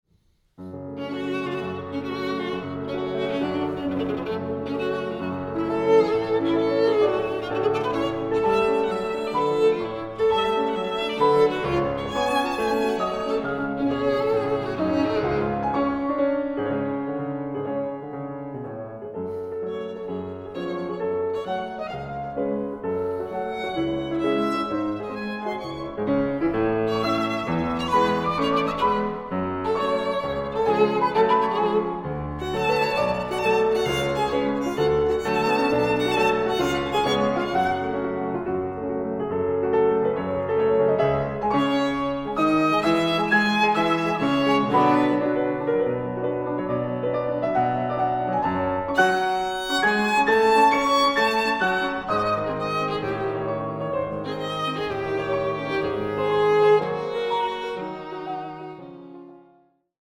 Sonatas for Piano and Violin
Piano
Violin